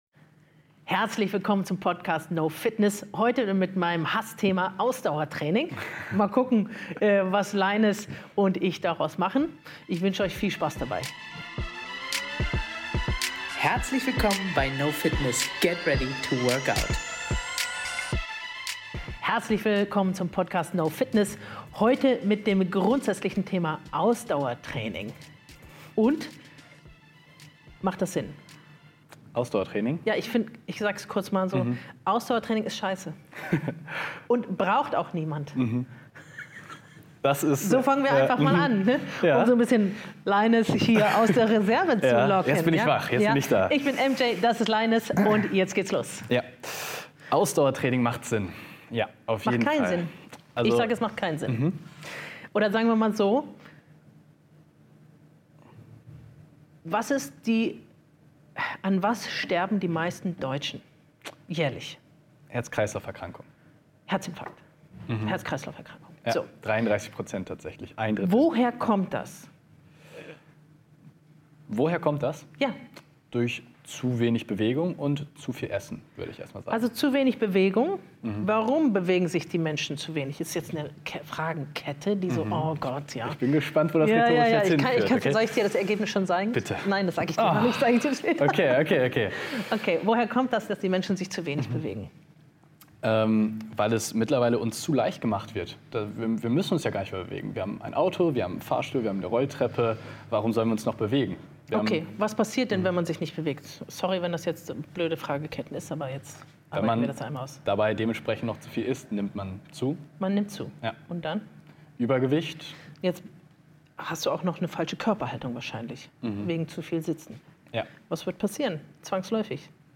Stattdessen betonen sie die Bedeutung von Krafttraining, insbesondere der Beinkraft, als Schlüssel zur Fitness und Freiheit im Alltag. Mit praktischen Tipps wie Treppensteigen oder Stuhlübungen zeigen sie, wie einfach es sein kann, Beinkraft zu trainieren. Eine spannende Diskussion über die optimale Balance zwischen Ausdauer und Krafttraining.